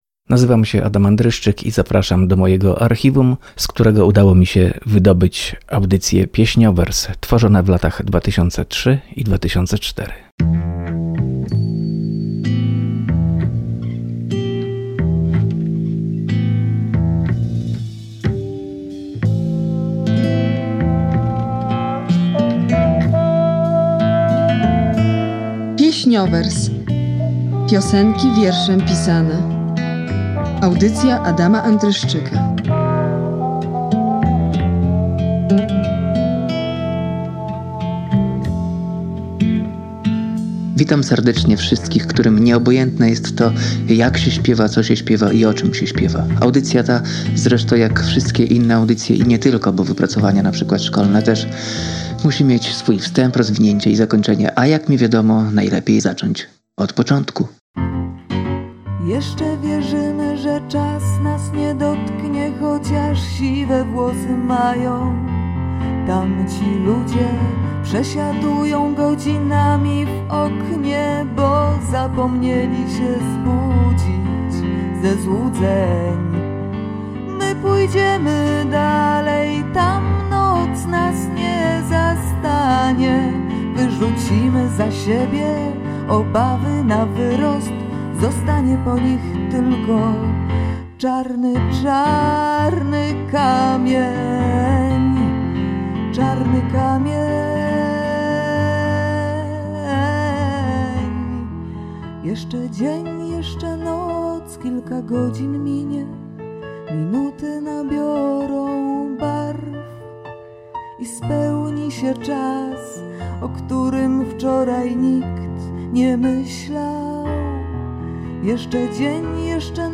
Audycja poświęcona piosence literackiej, tworzona w latach 2003-2004 dla Radia Olsztyn.